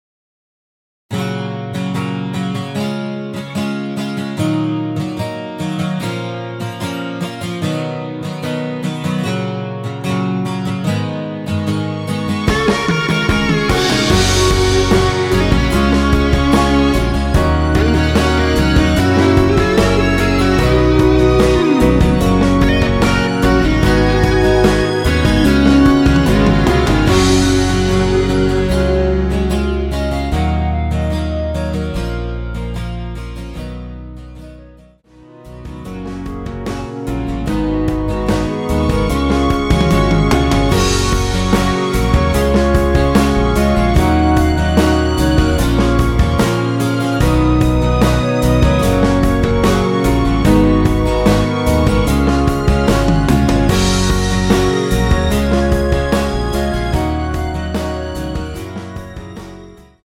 원키에서(-1)내린 멜로디 포함된 MR입니다.
F#
앞부분30초, 뒷부분30초씩 편집해서 올려 드리고 있습니다.
중간에 음이 끈어지고 다시 나오는 이유는